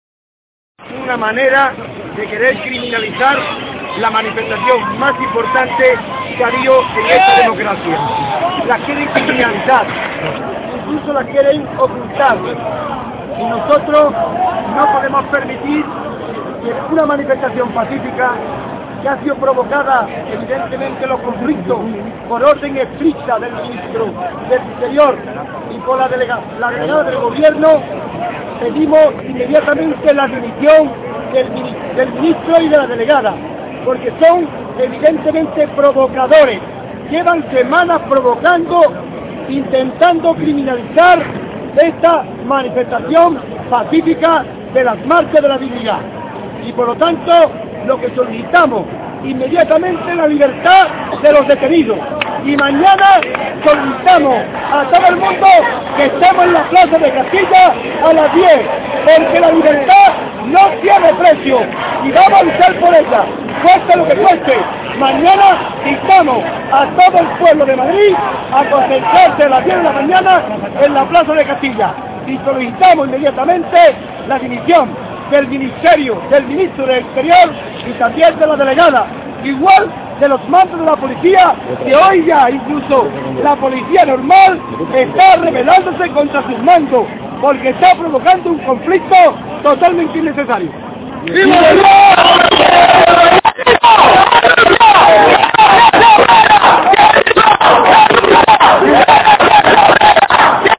La Haine recoge las declaraciones a la prensa que realiza Diego Cañamero, dirigente del SAT, en la Puerta del Sol: "Quieren criminalizar la manifestación más importante que ha habido en esta democracia".